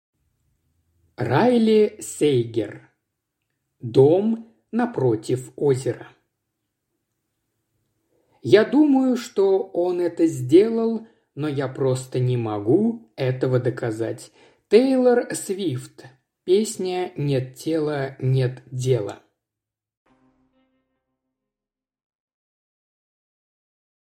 Аудиокнига Дом напротив озера | Библиотека аудиокниг
Прослушать и бесплатно скачать фрагмент аудиокниги